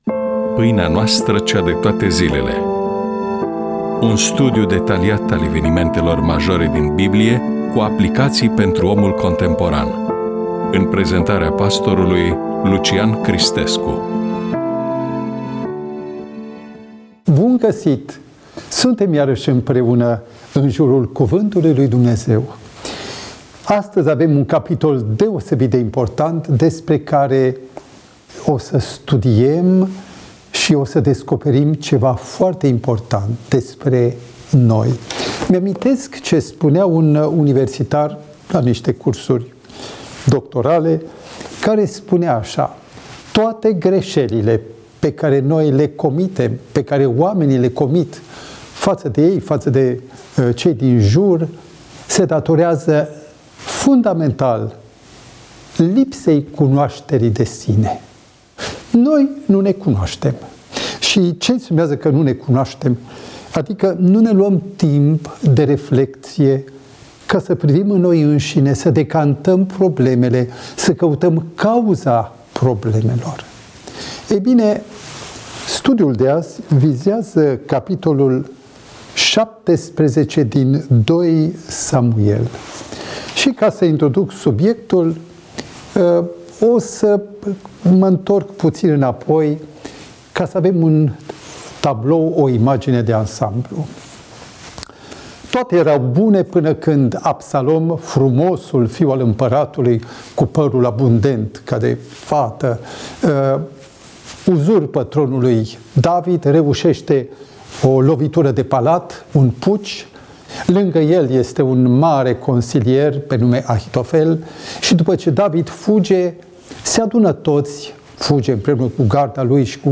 EMISIUNEA: Predică DATA INREGISTRARII: 30.01.2026 VIZUALIZARI: 31